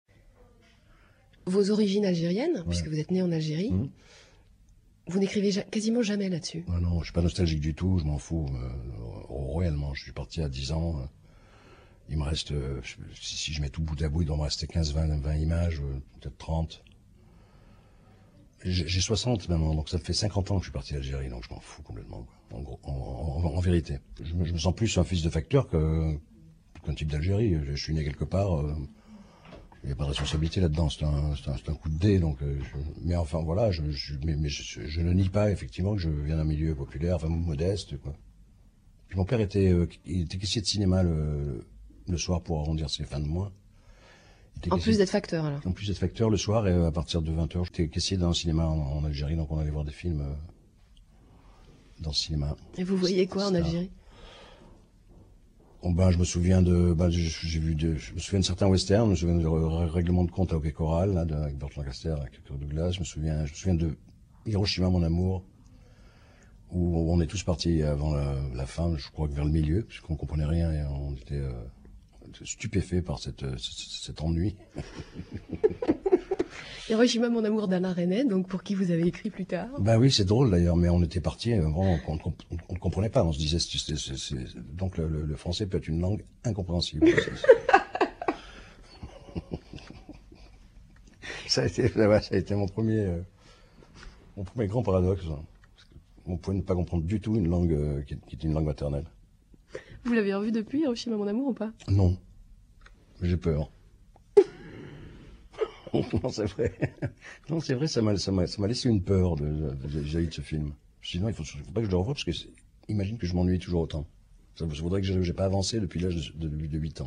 C’est encore Jean-Pierre Bacri, passionnant à écouter.